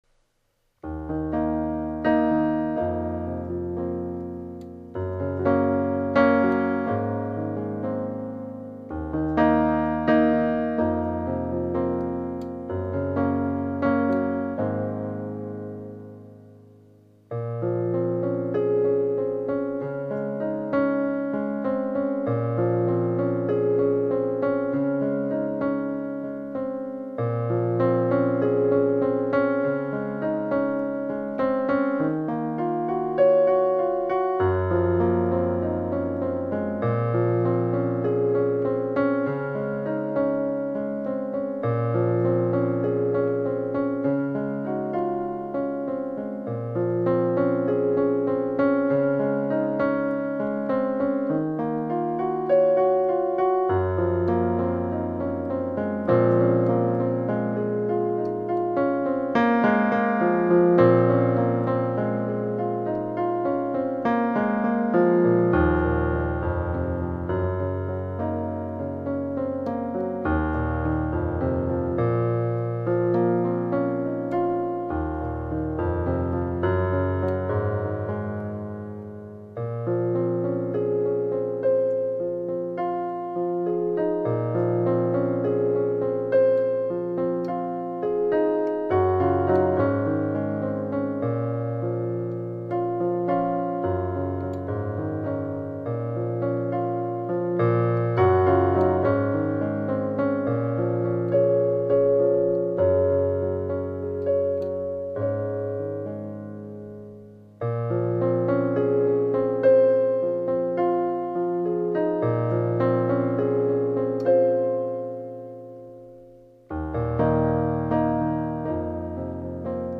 A two-minute piano composition is